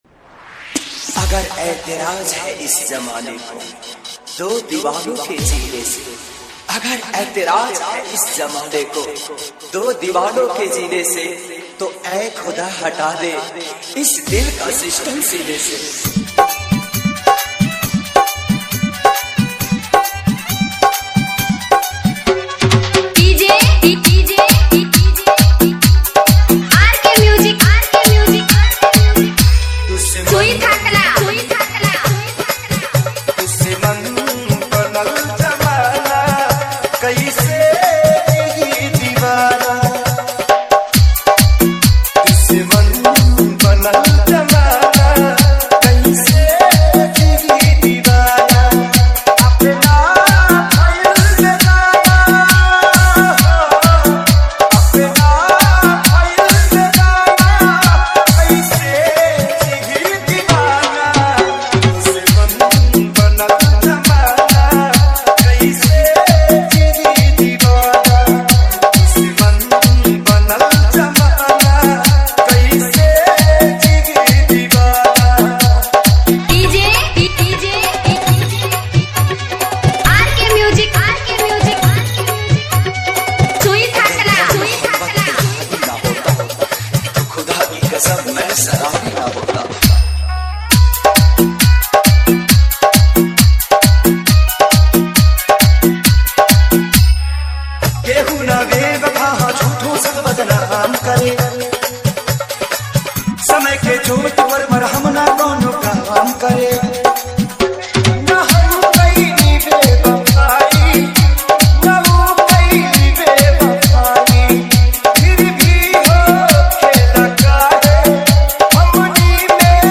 Bhojpuri Sad DJ Remix